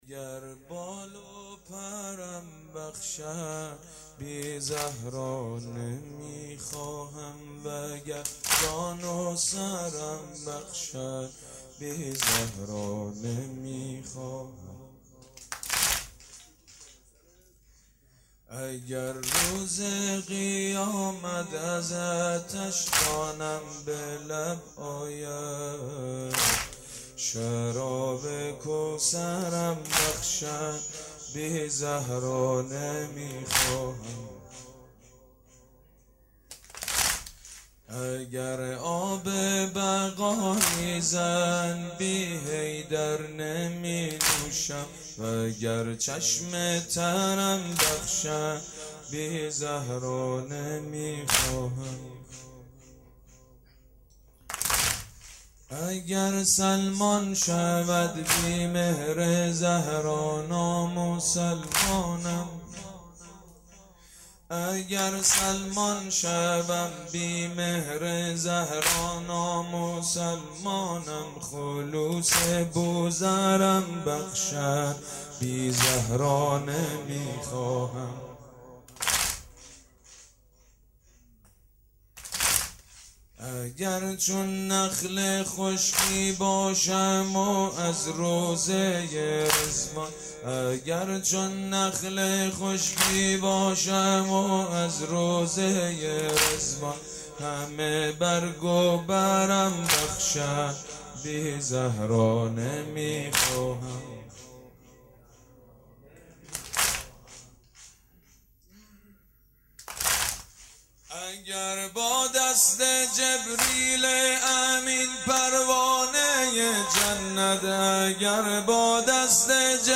مداحی و نوحه
سینه زنی، شهادت حضرت زهرا(س